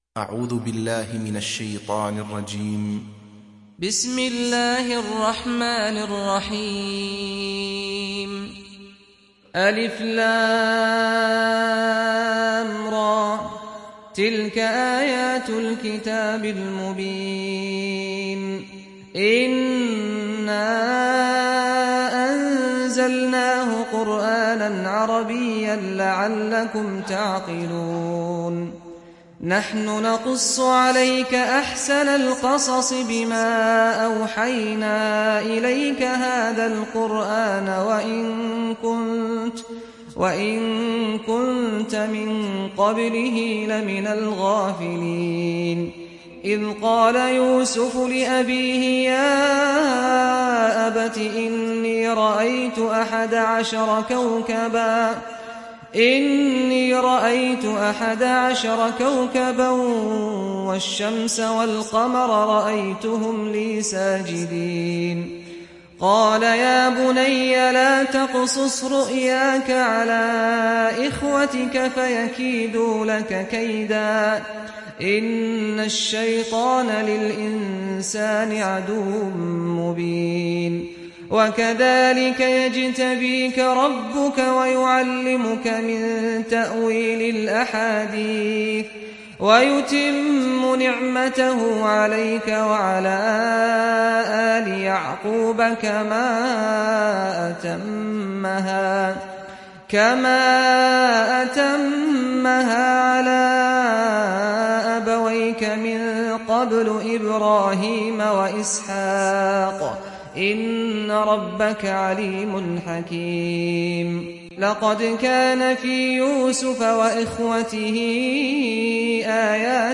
تحميل سورة يوسف mp3 بصوت سعد الغامدي برواية حفص عن عاصم, تحميل استماع القرآن الكريم على الجوال mp3 كاملا بروابط مباشرة وسريعة